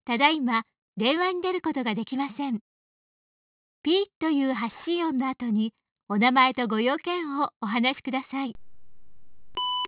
JapaneseAnswer1.wav